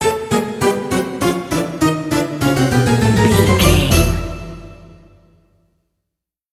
Aeolian/Minor
D
orchestra
harpsichord
silly
circus
goofy
comical
cheerful
perky
Light hearted
quirky